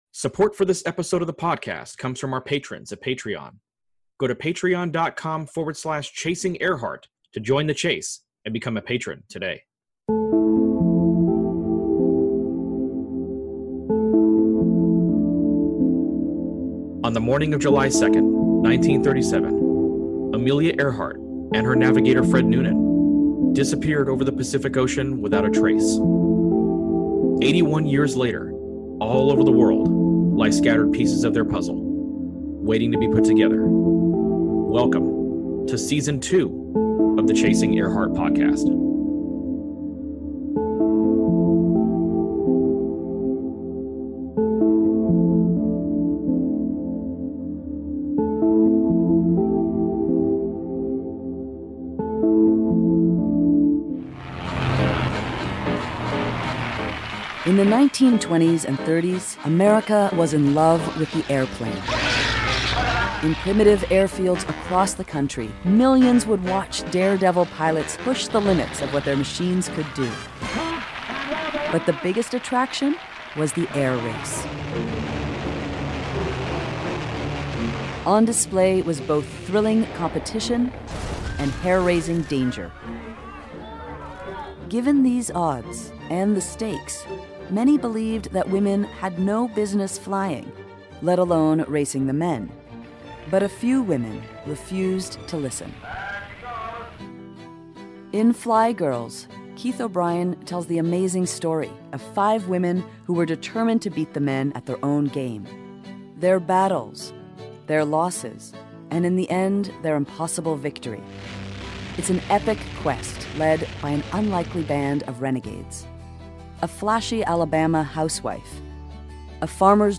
Fly Girls: A Conversation
joins us via Zoom